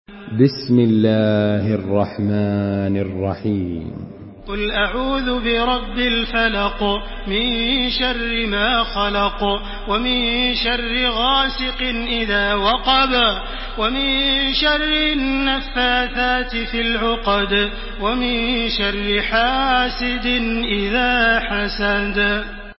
تحميل سورة الفلق بصوت تراويح الحرم المكي 1429
مرتل